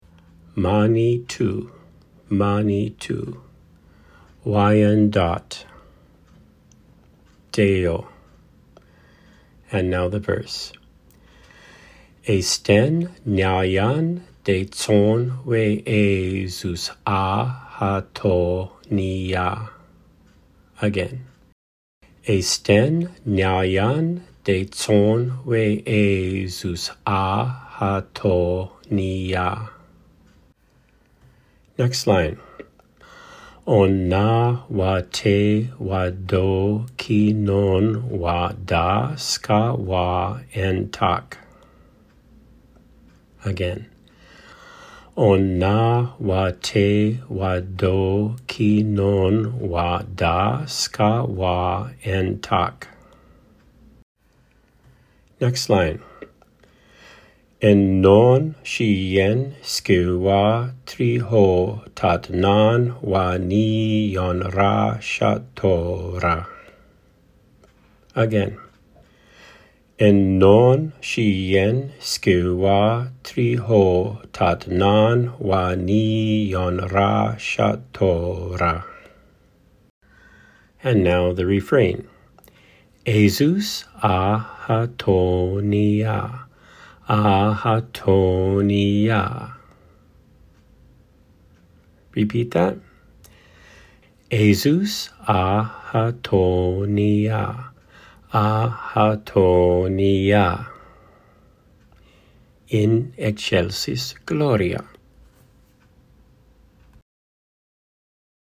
we hope to replace this simulation with genuine voice soon
Wyandot-Pronunciation.mp3